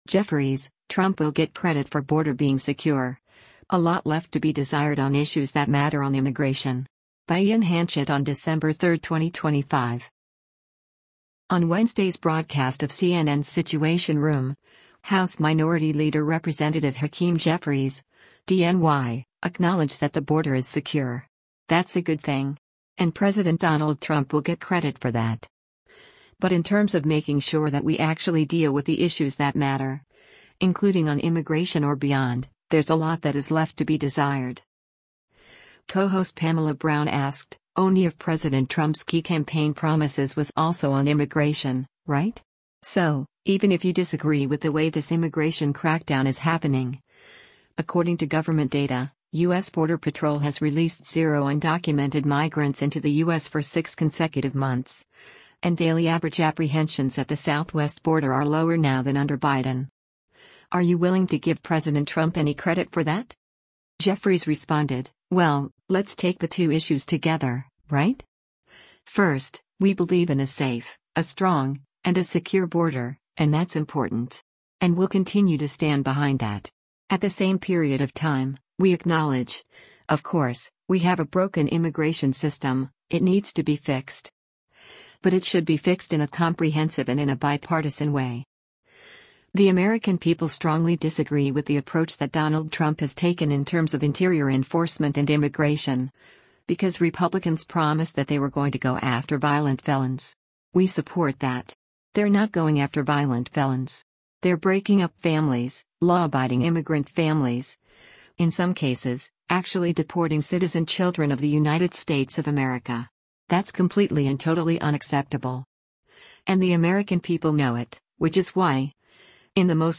On Wednesday’s broadcast of CNN’s “Situation Room,” House Minority Leader Rep. Hakeem Jeffries (D-NY) acknowledged that “The border is secure. That’s a good thing.” And President Donald Trump will “get credit for that.” But “In terms of making sure that we actually deal with the issues that matter, including on immigration or beyond, there’s a lot that is left to be desired.”